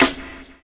Snare (17).wav